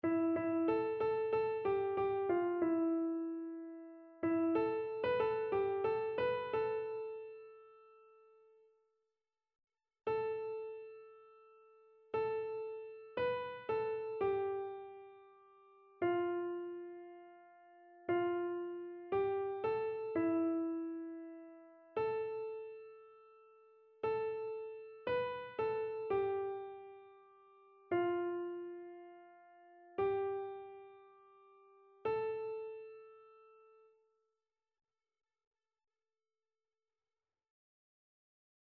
Soprano
annee-b-temps-ordinaire-26e-dimanche-psaume-18-soprano.mp3